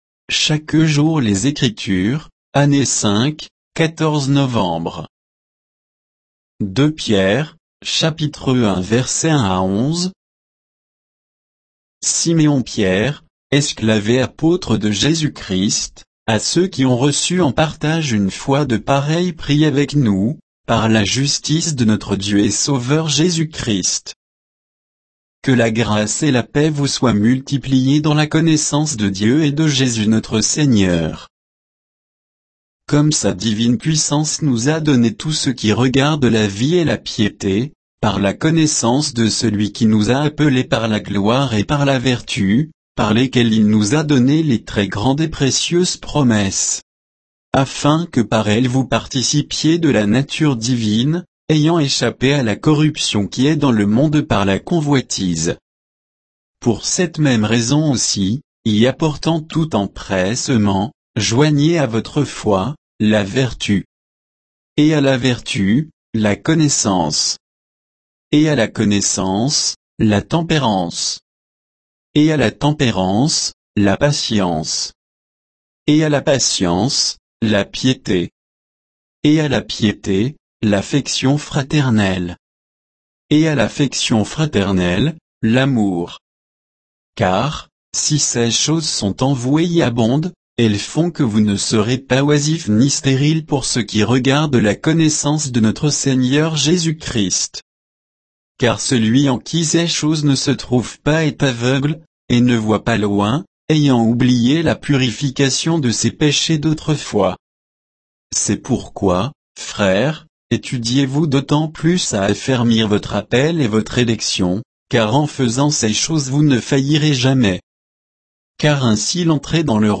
Méditation quoditienne de Chaque jour les Écritures sur 2 Pierre 1